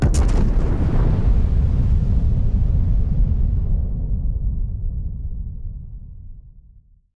WULA_Basttleship_Shootingsound_L.wav